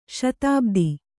♪ śatābdi